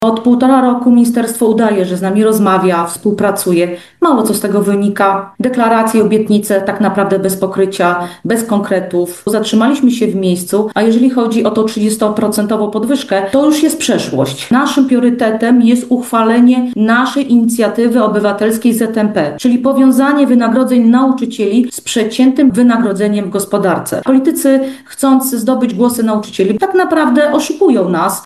w porannej rozmowie Radia Lublin. Jej zdaniem rozmowy toczące się pomiędzy ZNP, a ministerstwem nie przynoszą żadnych rezultatów.